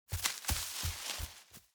sfx_bunny_scurry_v2.ogg